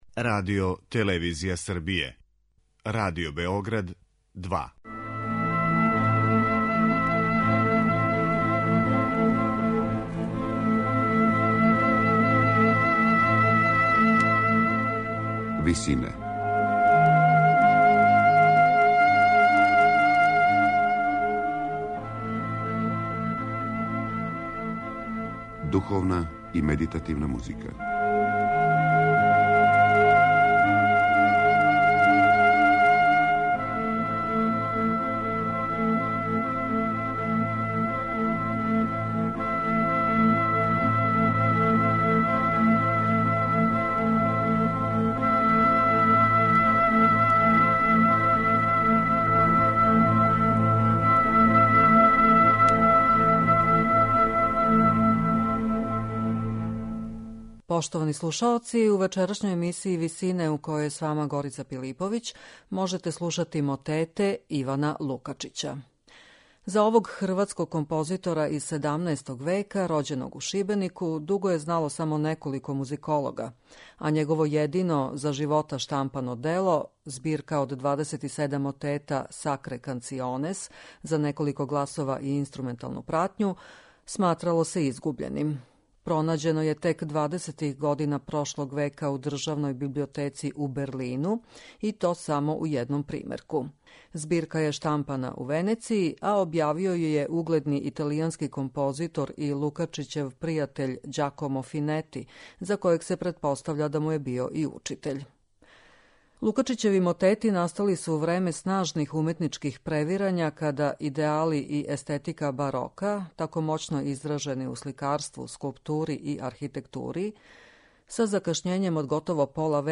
Духовни концерти Ивана Лукачића
за неколико гласова и инструменталну пратњу